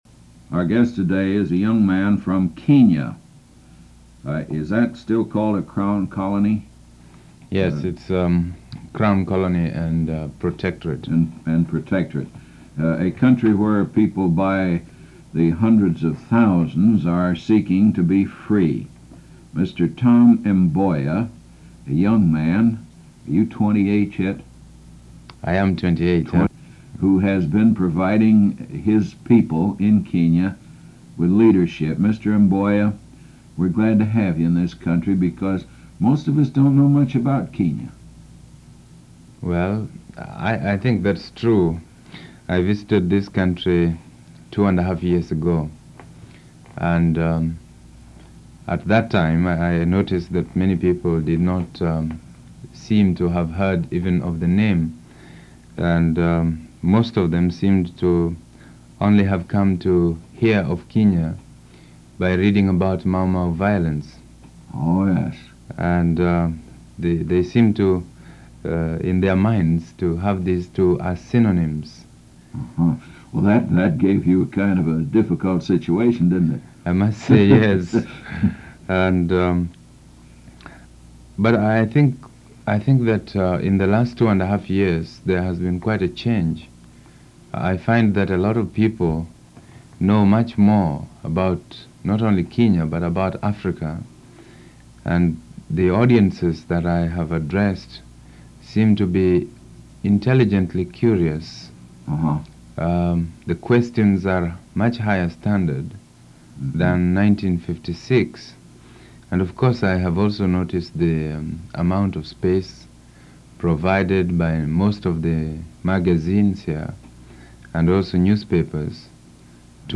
Click on the link here for Audio Player: Tom Mboya Interview
tom-mboya-interview.mp3